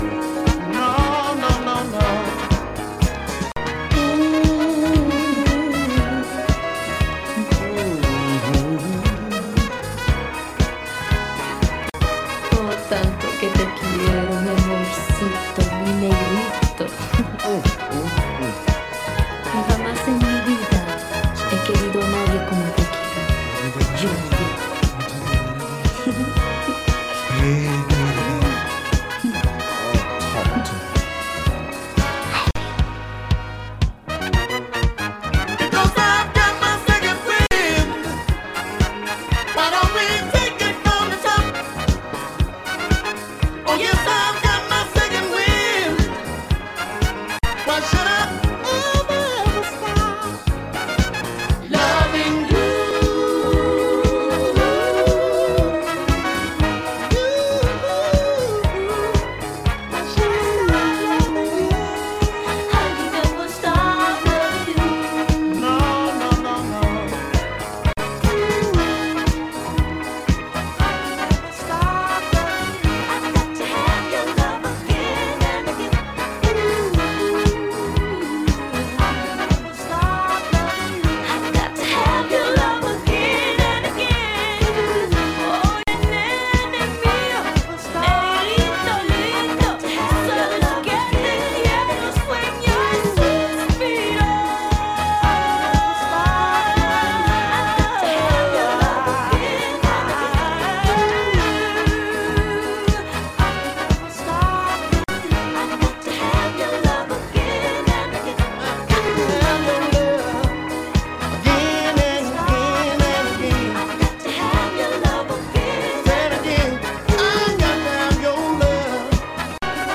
120弱のBPMでもう少しアップテンポに展開されていく